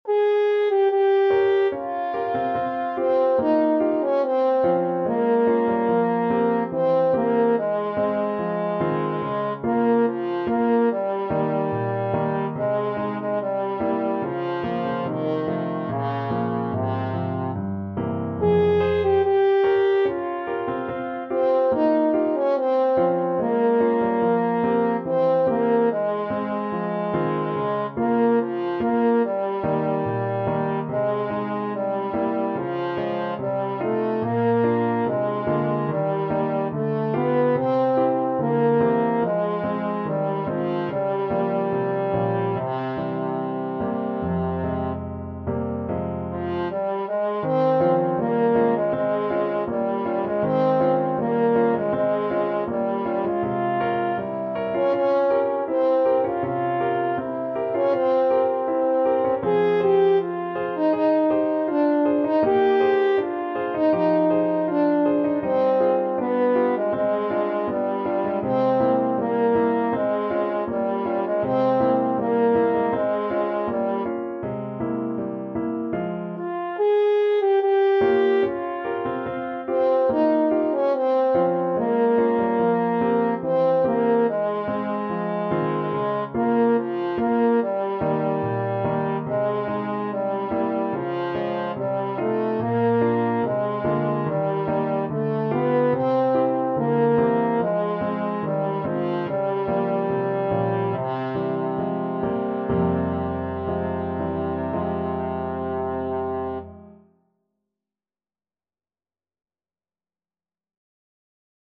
2/4 (View more 2/4 Music)
~ = 72 Andantino (View more music marked Andantino)
Classical (View more Classical French Horn Music)